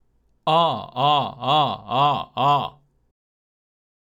まずは、グーの声で「え」の音、もしくは「あ」の音を区切って5回発声します。
※見本音声(「あ」の音)
h01_vibrato_G_a5.mp3